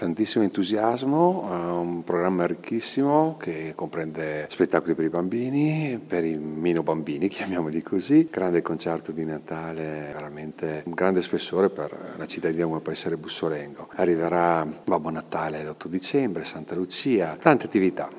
anche l’entusiasmo del Sindaco di Bussolengo, Roberto Brizzi:
sindaco-brizzi-online-audio-converter.com_.mp3